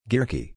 pronounced like "gearky") has two main goals: